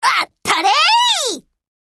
Ship Voice Miyuki Attack.mp3